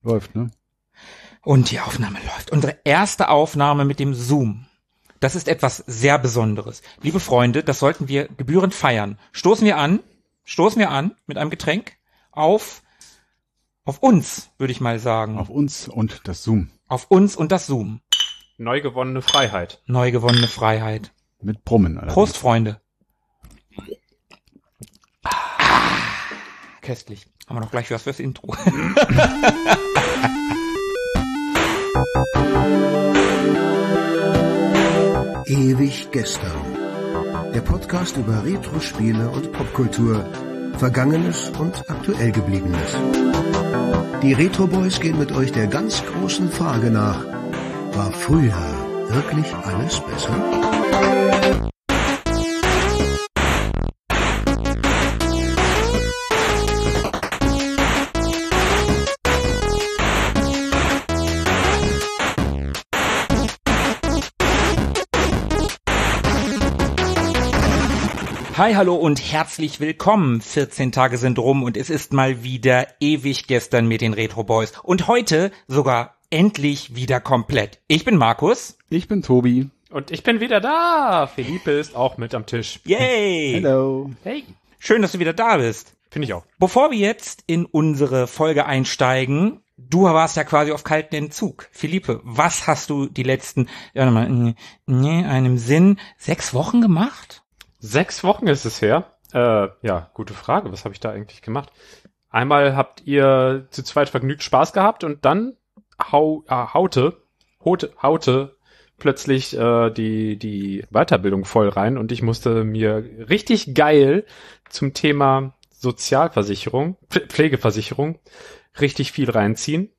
Introsprecher Hans-Georg Panczak (Ja, der.)